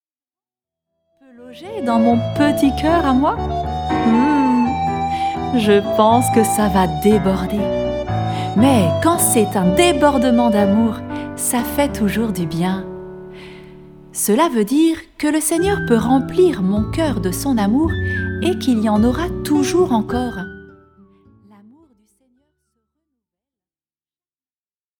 Mini-catéchèse